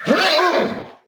hurt3.ogg